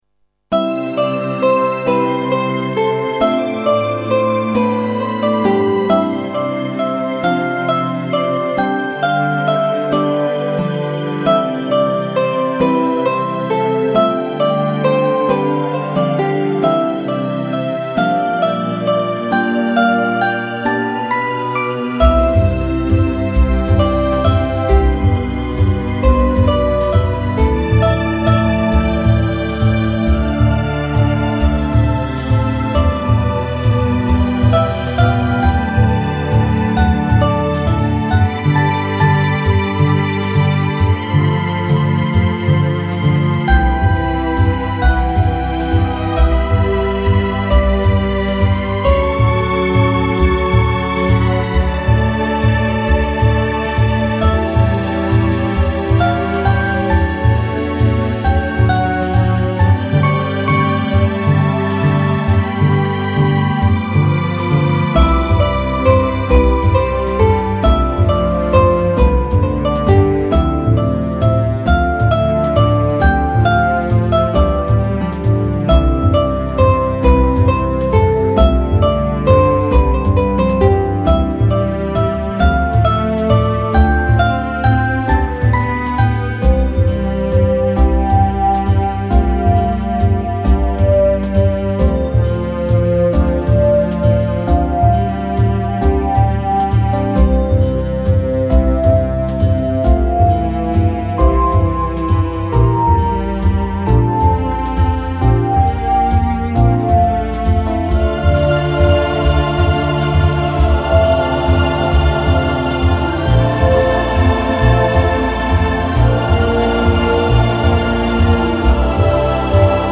・ブルースのリズムのようなワルツ（？）